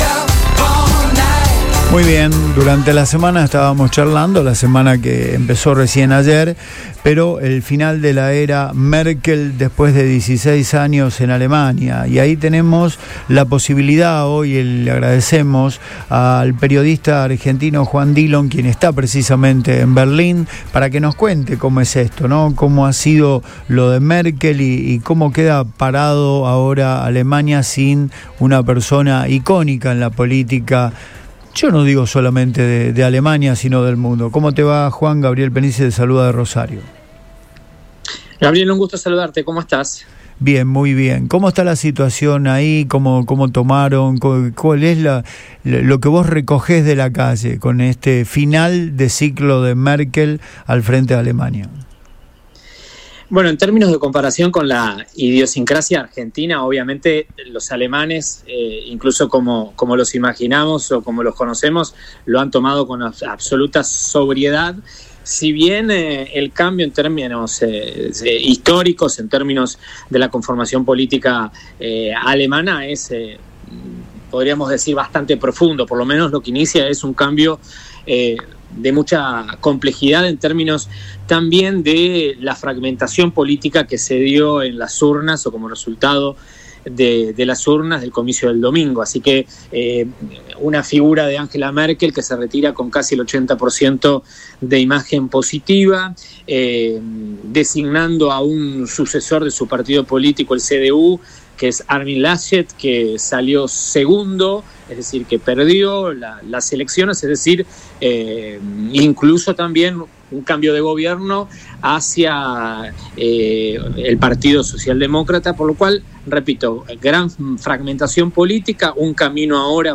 EN RADIO BOING